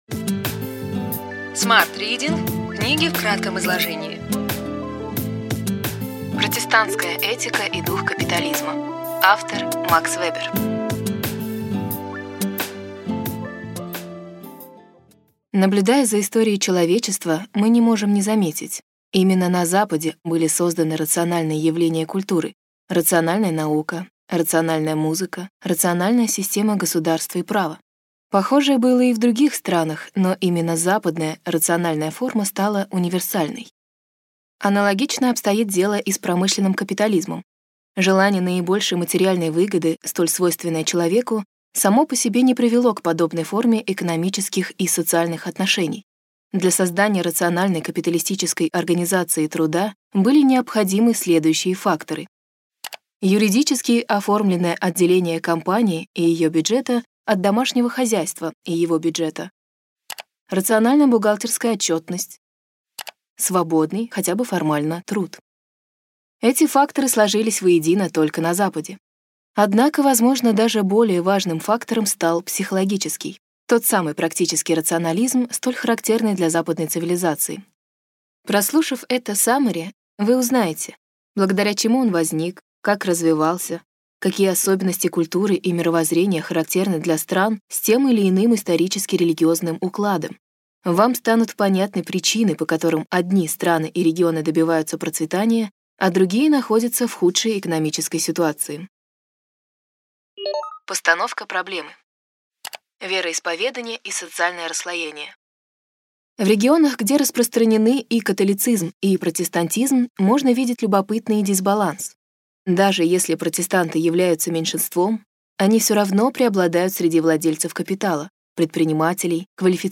Аудиокнига Ключевые идеи книги: Протестантская этика и дух капитализма. Макс Вебер | Библиотека аудиокниг